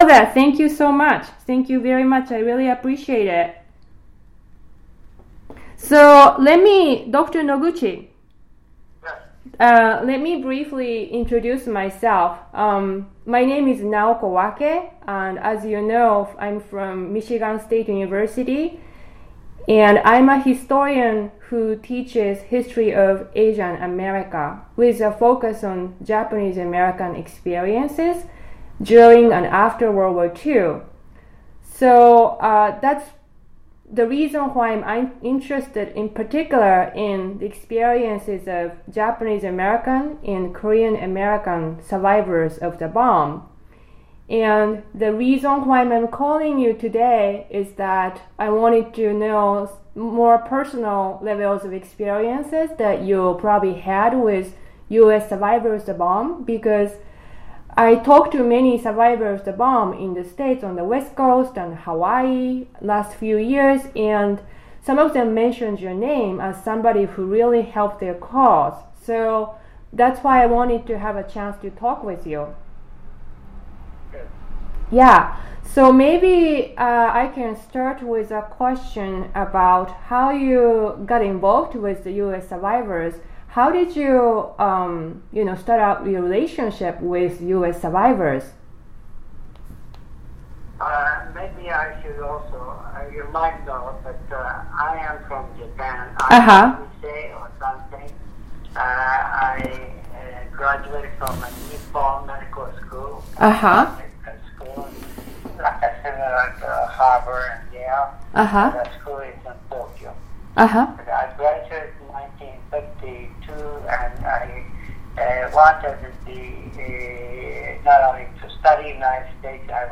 Oral history interview with Thomas Noguchi, 2014 March 27